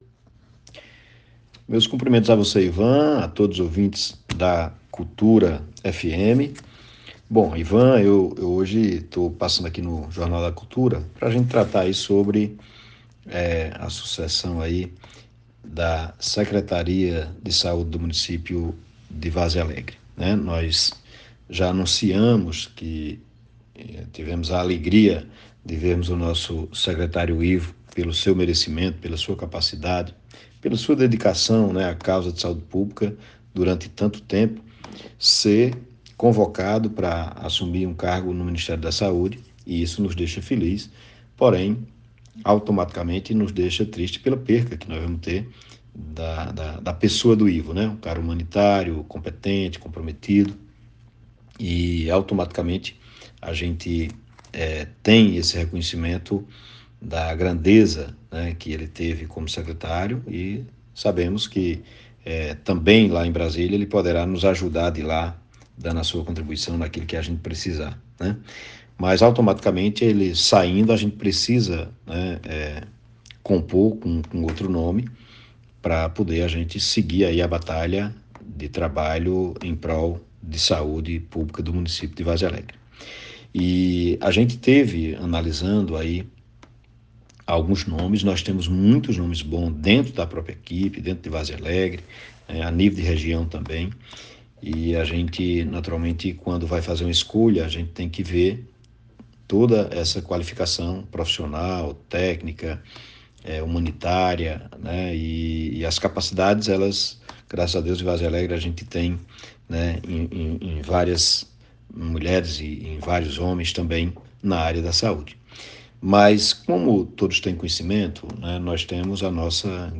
Acompanhe o áudio do prefeito com a informação: